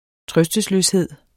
Udtale [ ˈtʁœsdəsløsˌheðˀ ]